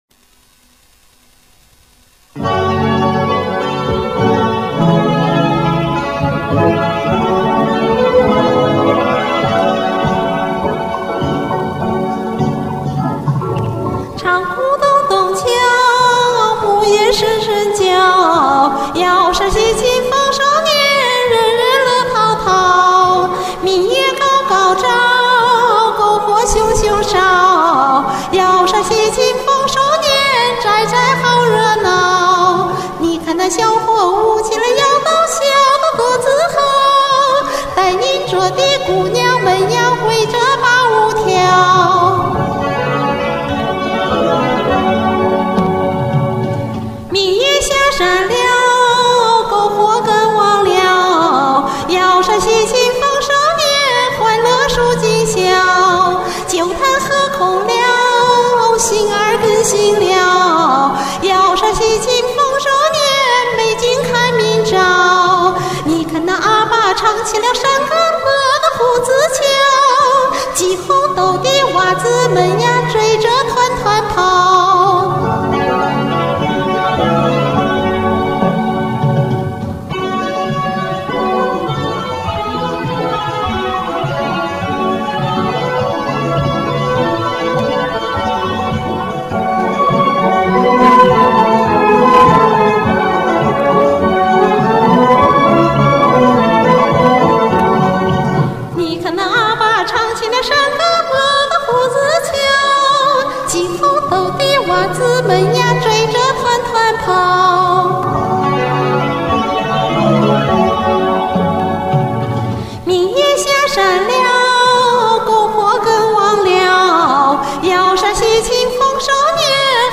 只好自己做了消音伴奏，录了两遍。
欢快跃动， 如同瑶族姑娘围着篝火起舞！
欢快喜庆
明亮通透 好棒！